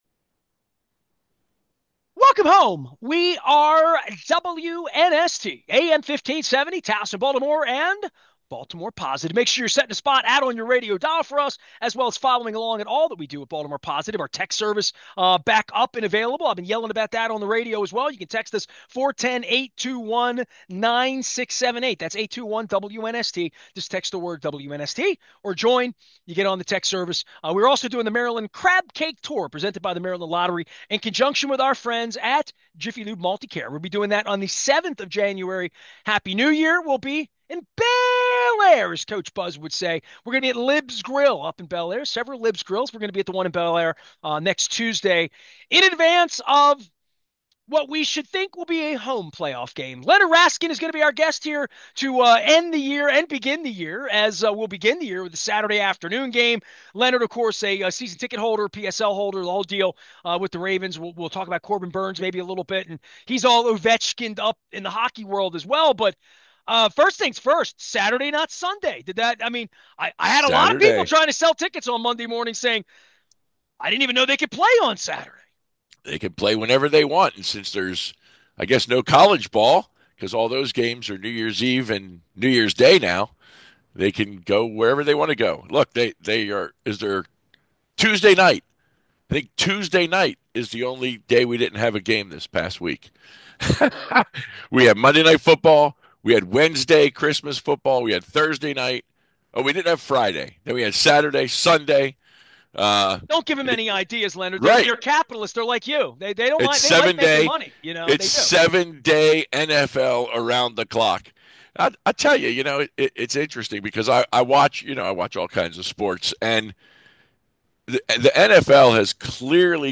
Intelligent conversation about all things Baltimore.